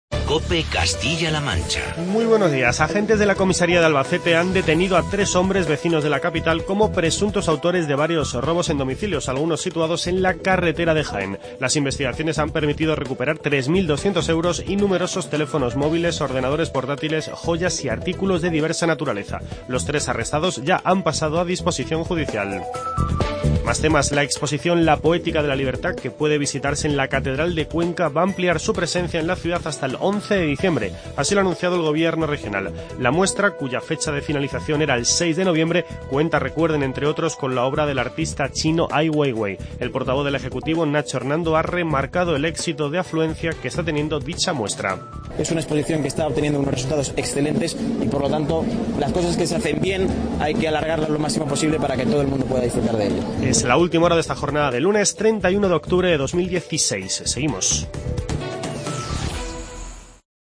Informativo COPE Castilla-La Mancha